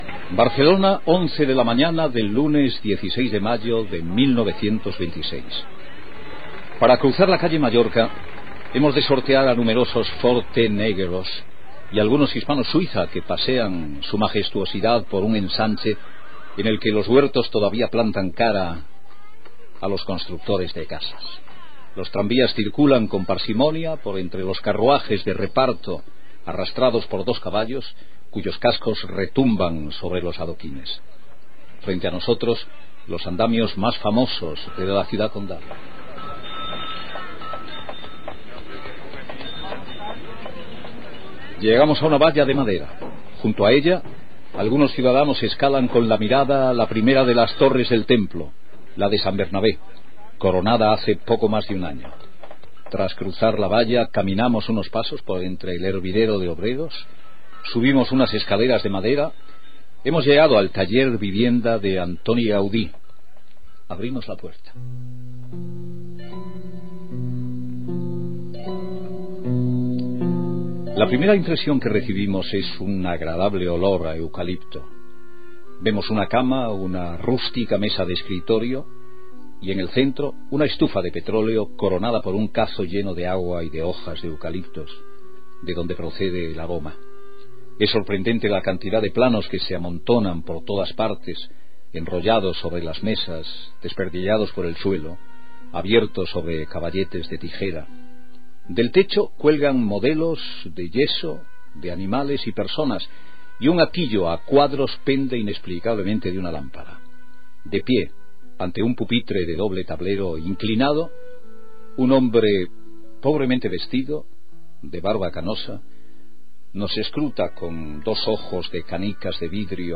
Entrevista fictícia a l'arquitecte Antoni Gaudí.
Ficció